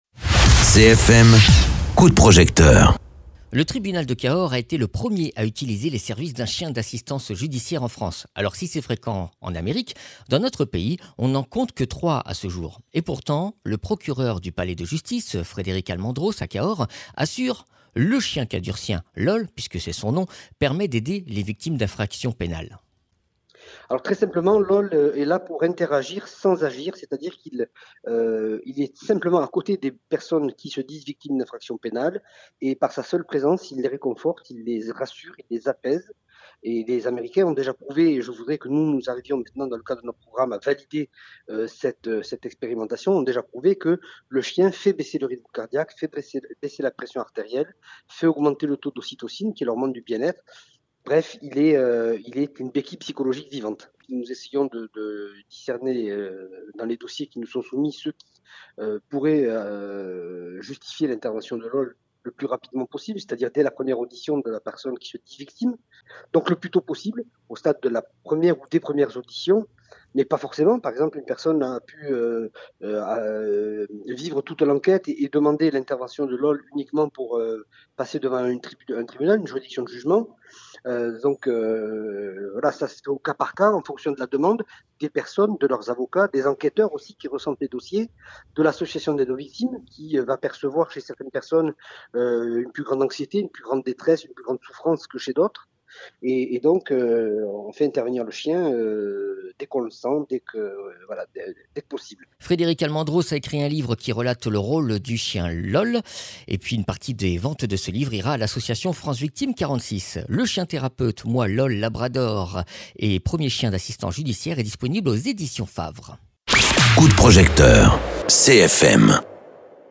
Interviews
Invité(s) : Frederic Almendros, procureur au tribunal de Cahors